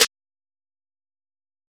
snare 13.wav